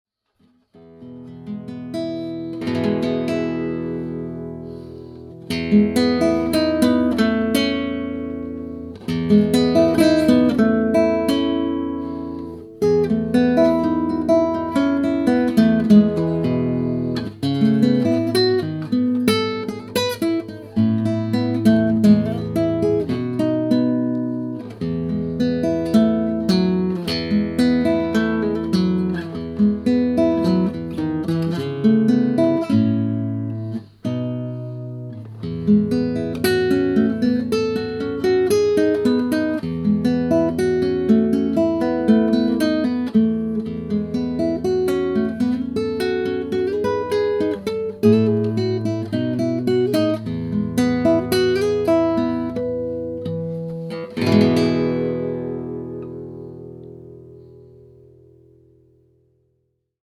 These are transformer balanced, small diaphragm electret condenser mics that are powered by a single 1.5V battery.
Below is a stereo recording of nylon string guitar, made with a spaced pair of these Oktava MKE-9 microphones.
Oktava_MKE9_SpacedPair_Nylon_Guitar.mp3